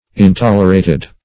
Intolerated \In*tol"er*a`ted\, a.